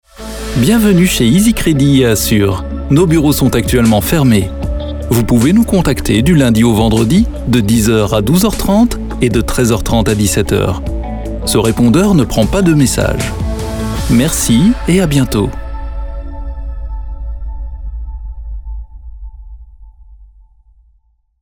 répondeur
posé